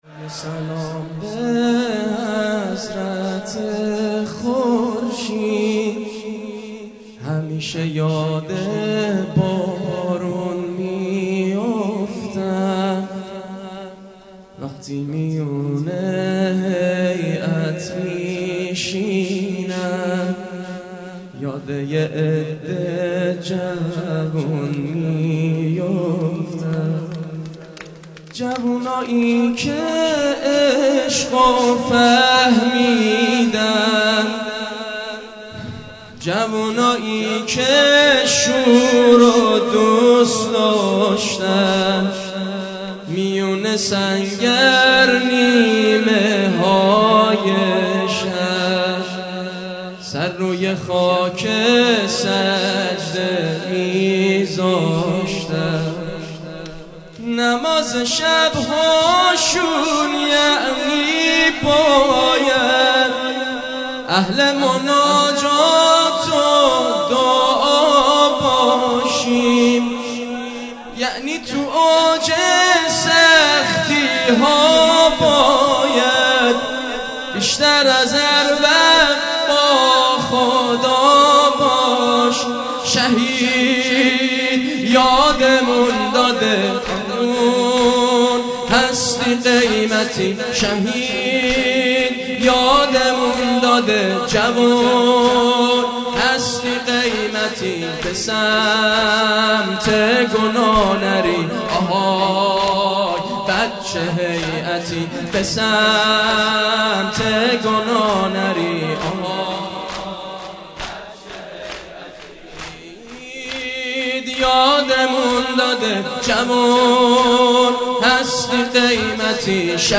زمینه (شهید یادمون داده، جَوون هستی قیمتی ...)
همه چی عالیه فقط یه انتقاد کوچولو : چرا کیفیت مداحیا ایقد پایینه ؟؟؟؟؟؟؟؟؟؟؟؟؟؟؟